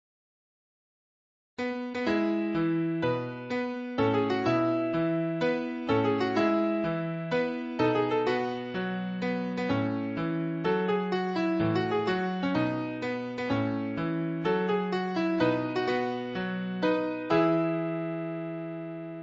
Type: Baby Music Toy Brand Name
3)Spring-operated,wind-up through pull-string